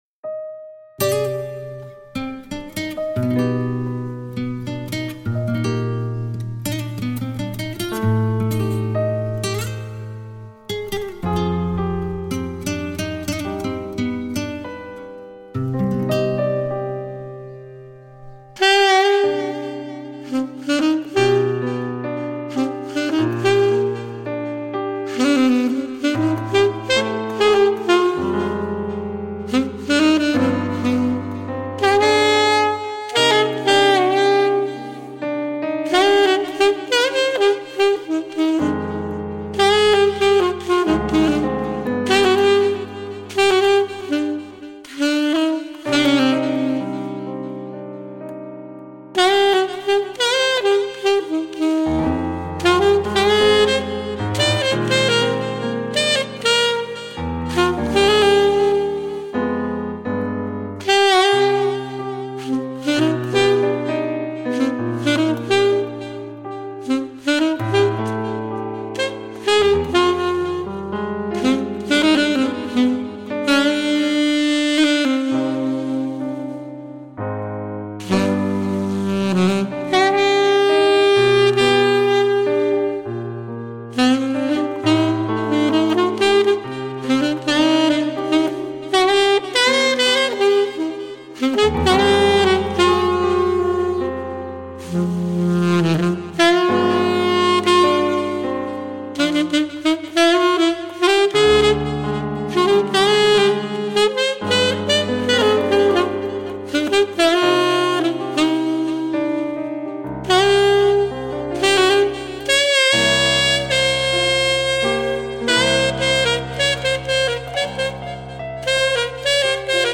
SAX - Latino